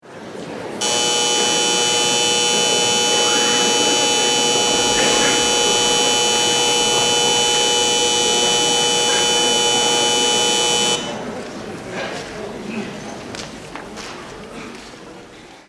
关于恒定蜂鸣声音效素材的高质量PPT_风云办公